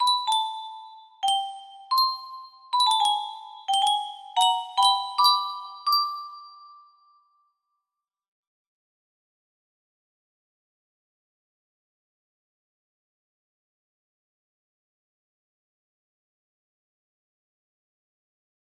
Twilight Dance music box melody